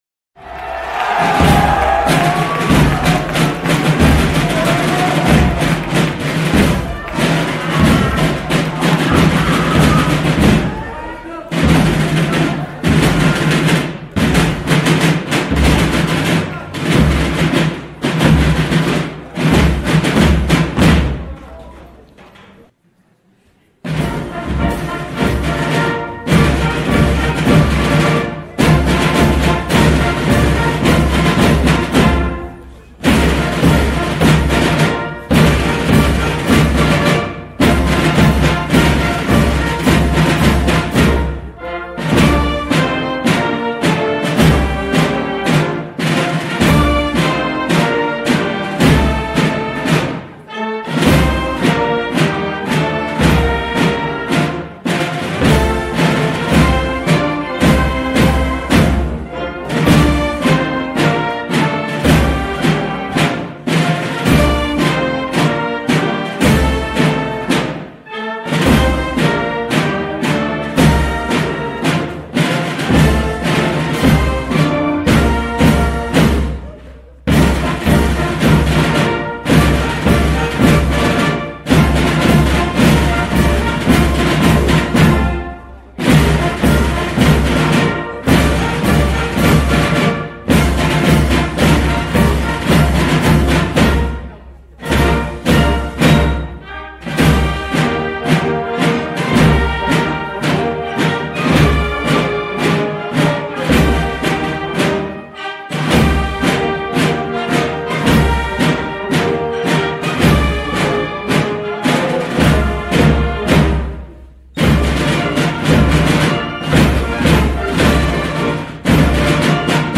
La musique fétiche de le clique de la Laurentia dans les années 1960-1970 et qui donnait fière allure au défilé du corso fleuri :